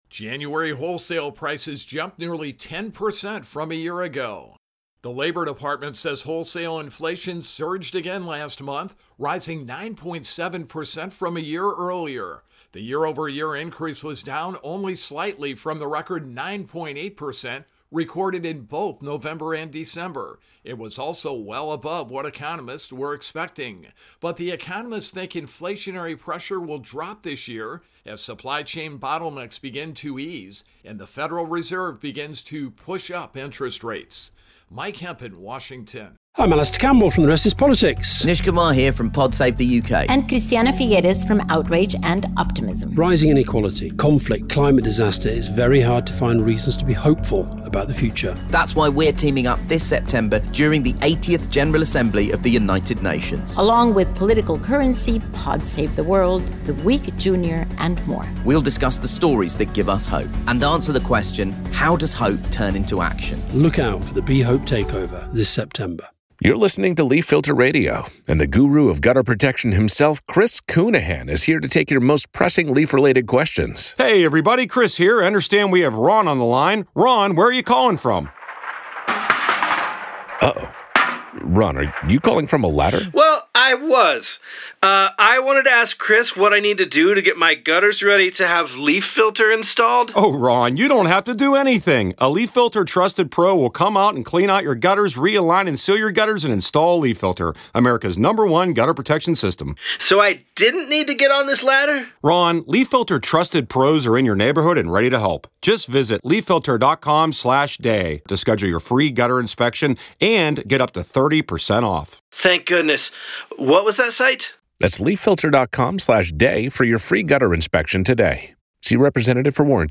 Intro and voicer for Producer Prices